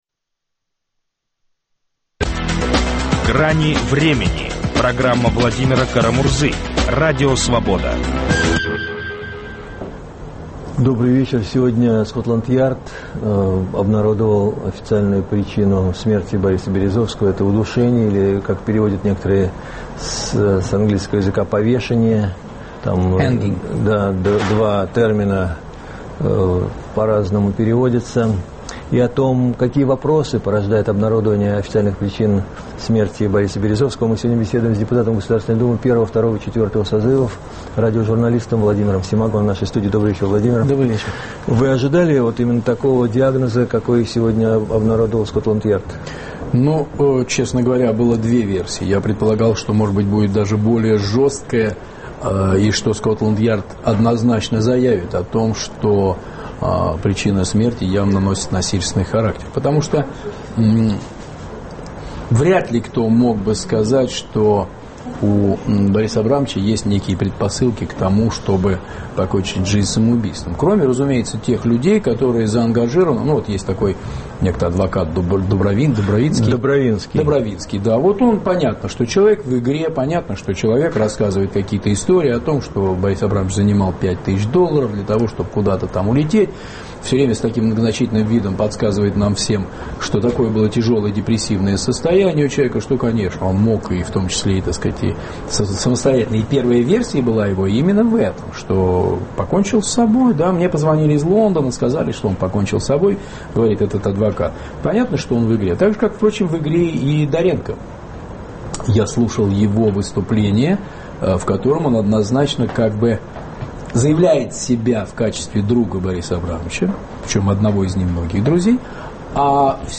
Какие вопросы порождает обнародование причин смерти Березовского? Об этом беседуем с бывшим депутатом ГД РФ Владимиром Семаго.